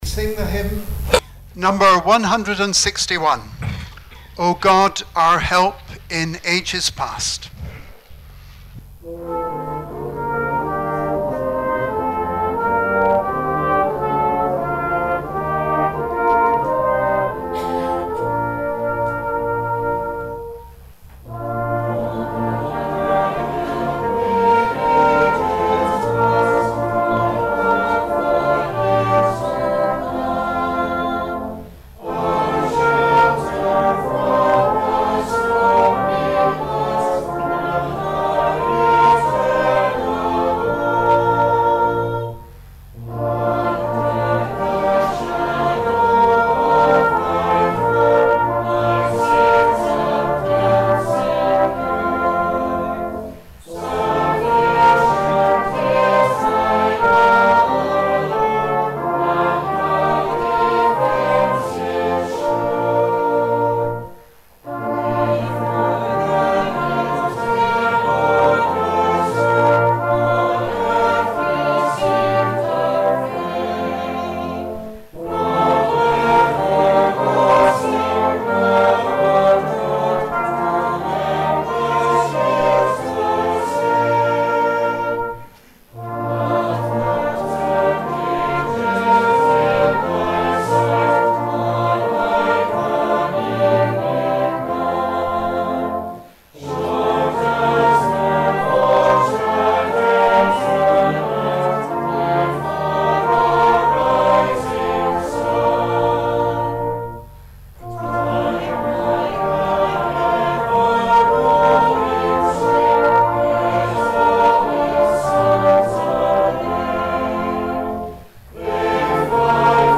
Remembrance Sunday - 10 November 2019
O God, our help in ages past'. hymn 161.